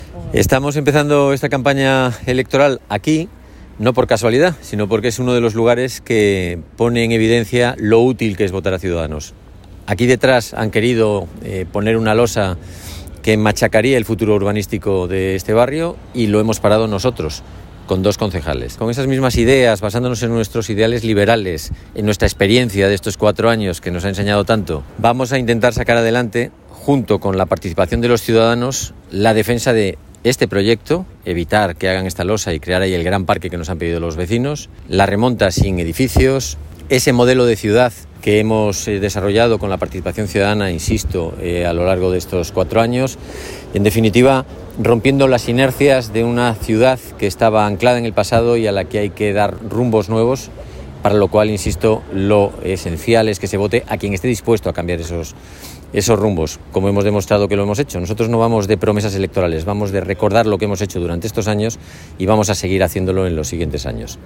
El candidato de Ciudadanos a la Alcaldía de Santander, Javier Ceruti, ha participado esta noche en el tradicional acto de pegada de carteles con la que se pone en marcha la campaña electoral de las elecciones municipales del próximo 28 de mayo.